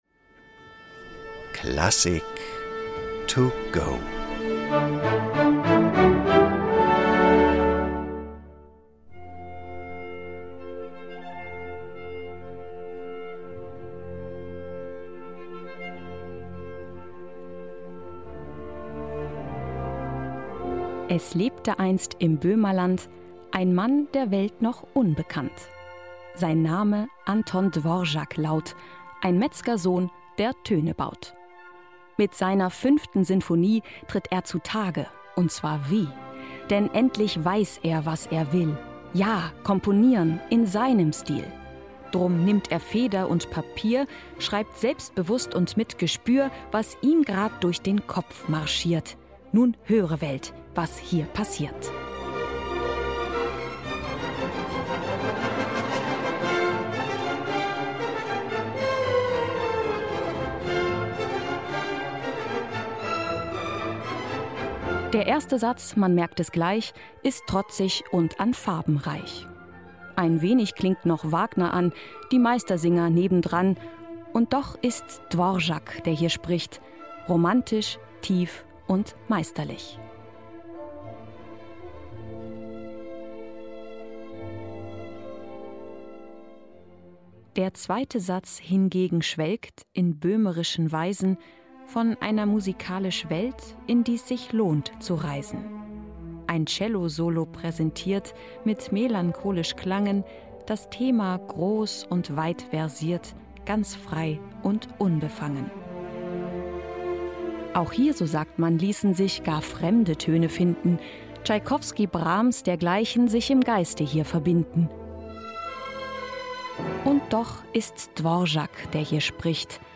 "Klassik to Go" - die digitale Werkeinführung zum Download!